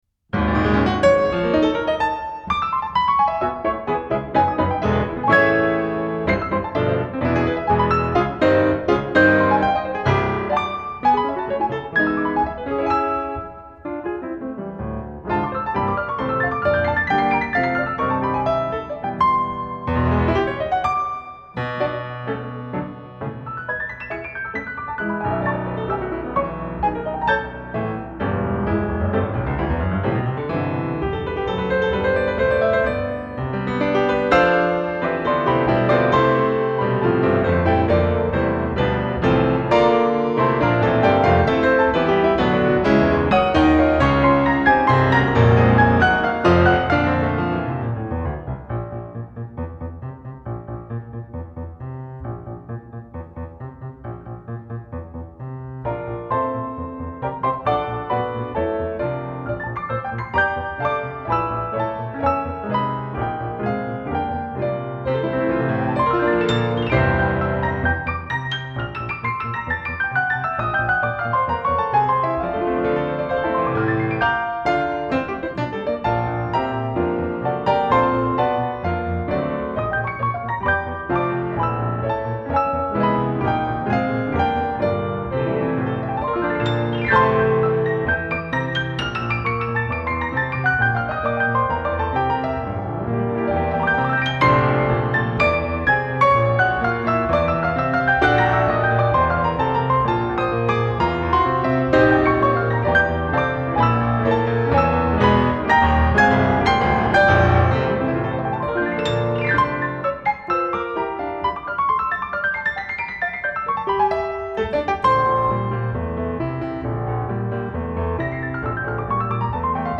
Genre : Alternative & Indie